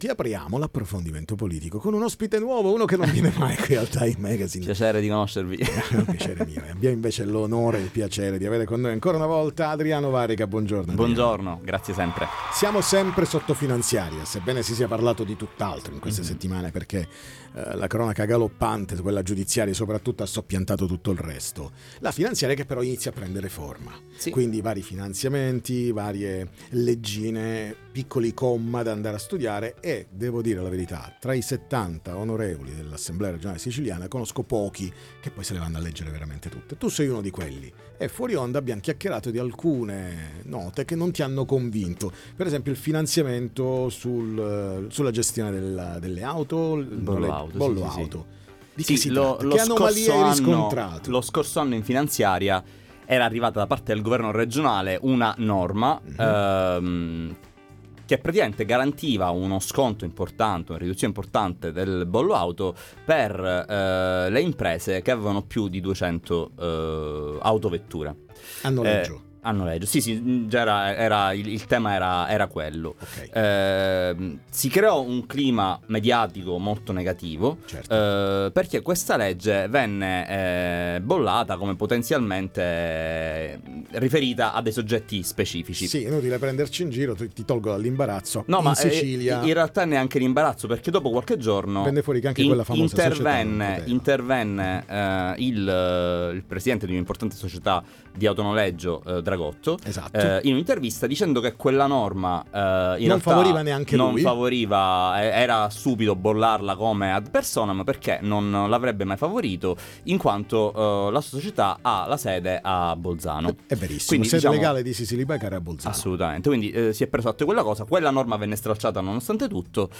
Approvato l’abbattimento del bollo auto per imprese, M5S: “Norma sospetta”, ne parliamo in studio con Adriano Varrica del M5S
Interviste Time Magazine